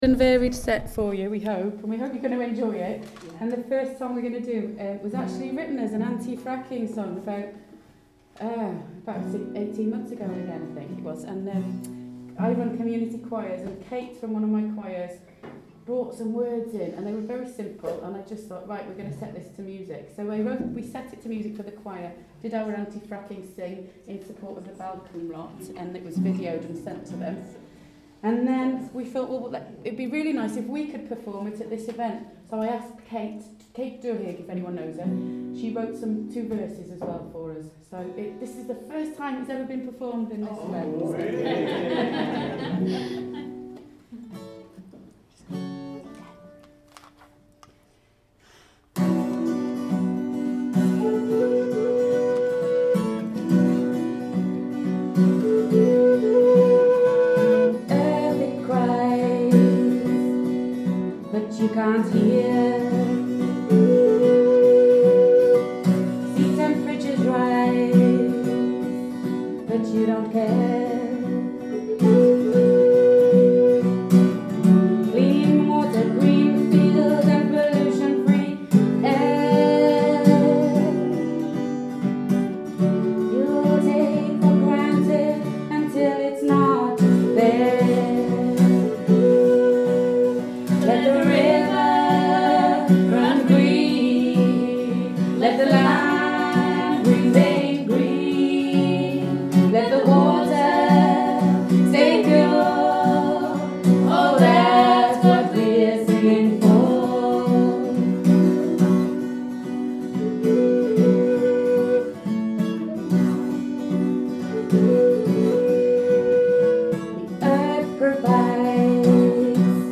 (recording done at a Friends of the Earth conference we were asked to perform at earlier in the year)